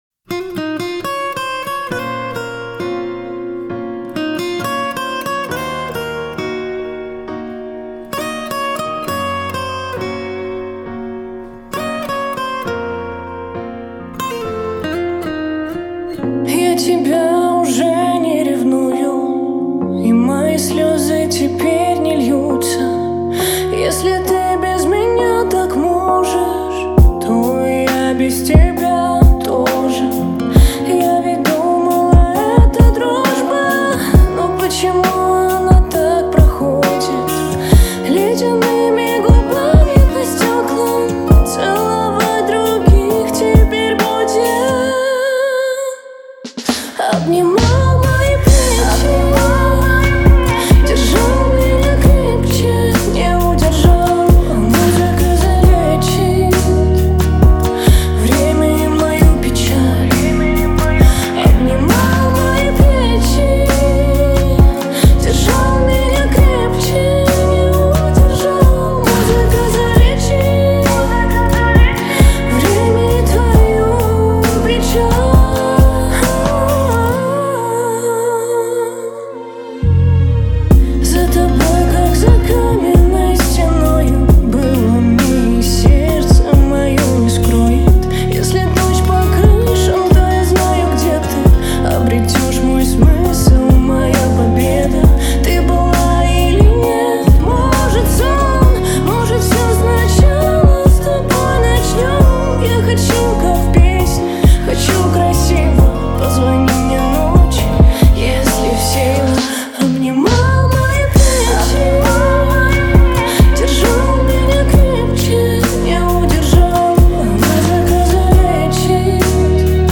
теплым звучанием гитары
эмоциональным вокалом